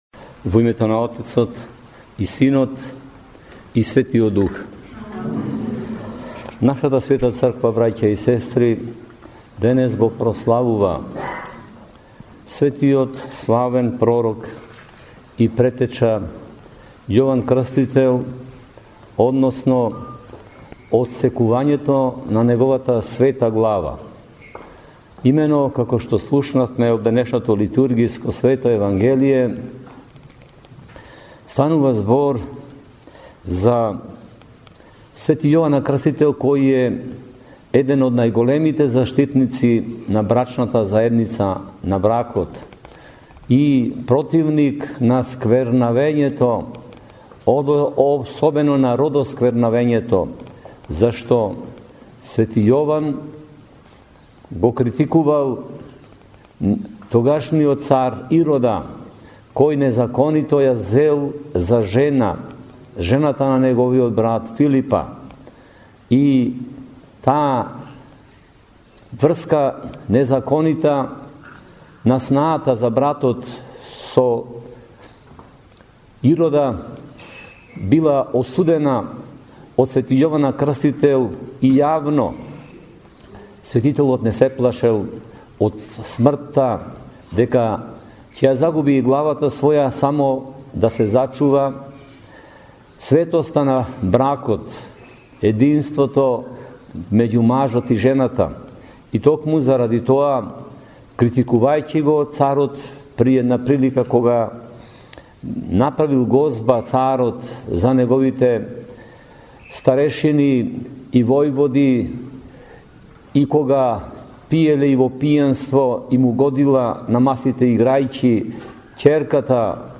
На 11.09.2014 г, на денот на отсекувањето на главата на св. Јован Крстител, Митрополитот Преспанско-Пелагониски г. Петар, отслужи Божествена Литургија во манастирот посветен на св. Јован Крстител во Слепче.